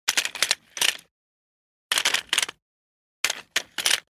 دانلود آهنگ کلیک 34 از افکت صوتی اشیاء
جلوه های صوتی
دانلود صدای کلیک 34 از ساعد نیوز با لینک مستقیم و کیفیت بالا